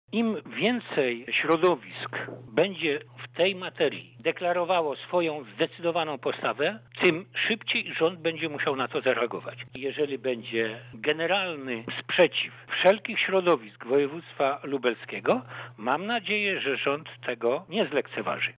O szczegółach mówi Teodor Kosiarski, burmistrz Łęcznej